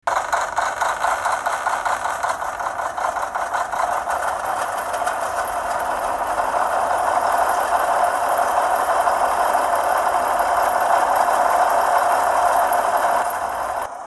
SFX3:     BigFoot sound 3
bigfoot-sound-3.mp3